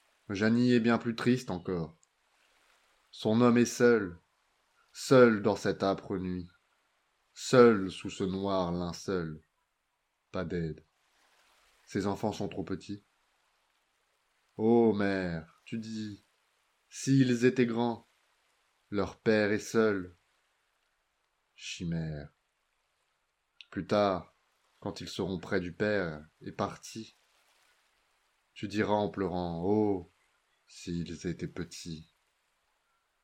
Voix off
22 - 37 ans - Contre-ténor